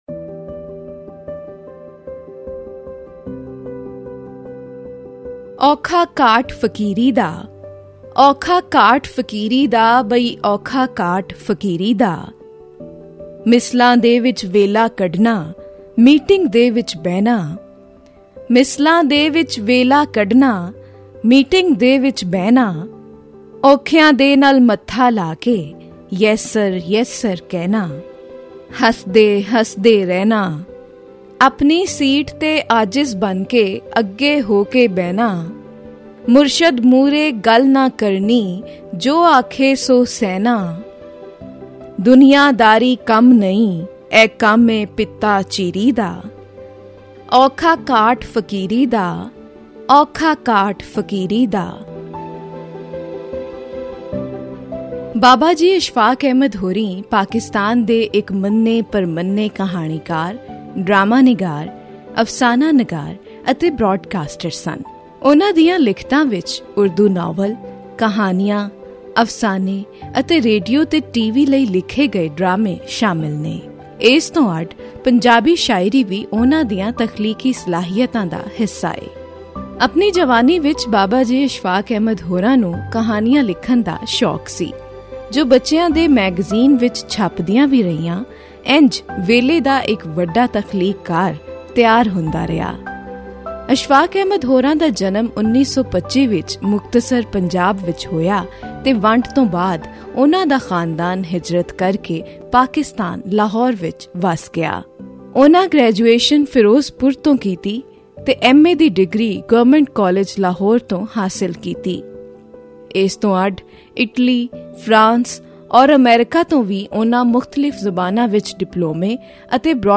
ਇਹ ਰਿਪੋਰਟ..